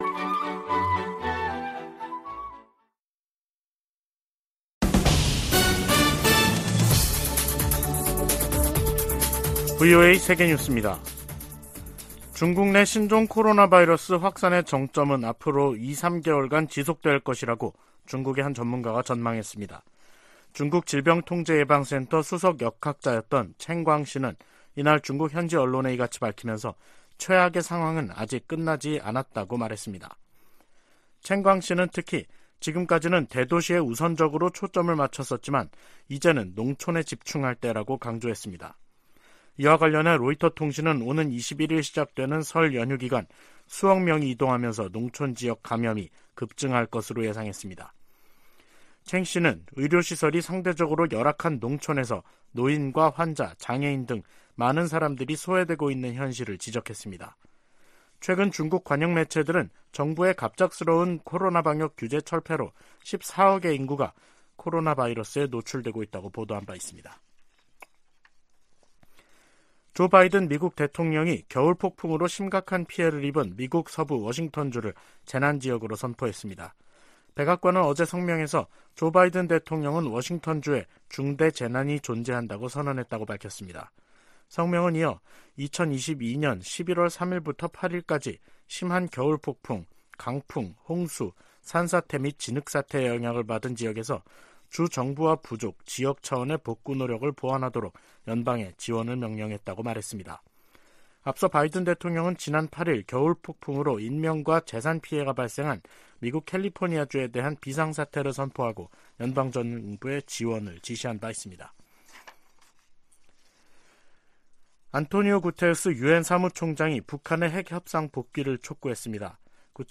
VOA 한국어 간판 뉴스 프로그램 '뉴스 투데이', 2023년 1월 13일 2부 방송입니다. 미 국무부는 미국과 한국, 일본이 북한의 핵과 탄도미사일 프로그램을 심각한 위협으로 받아들이고 있으며, 이를 막기 위해 3자 차원의 대응을 강화하고 있다고 밝혔습니다. 미국 백악관은 윤석열 한국 대통령의 자체 핵보유 언급과 관련해 한반도의 완전한 비핵화 입장에 변함이 없다고 강조했습니다.